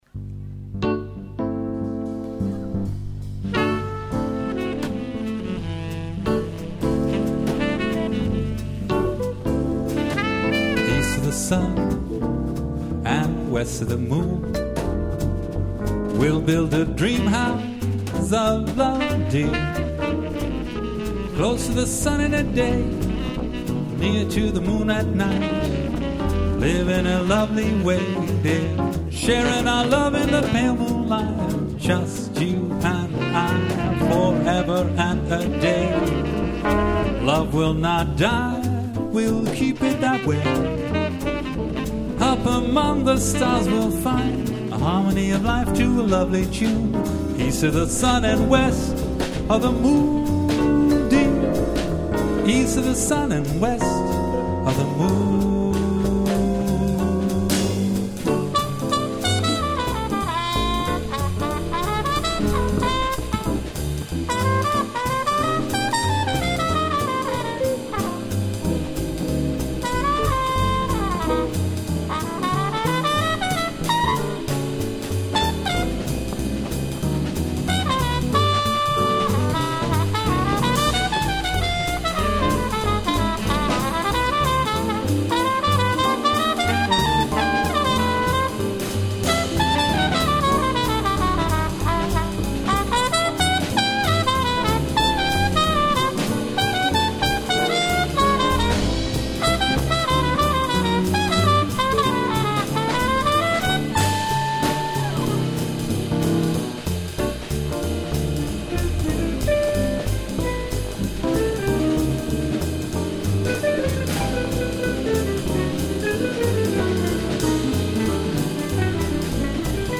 Zang
Trompet
Tenorsax
Gitaar
Piano
Drums